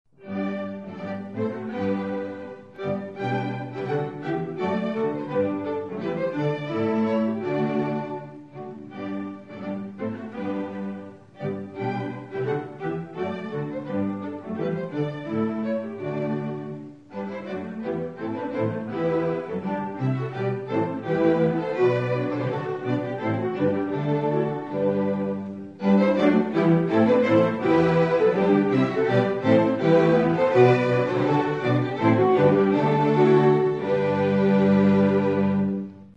Renaissance and early baroque dances
Orchestra
The Accademia Monteverdiana Orchestra
alman-orchestra.mp3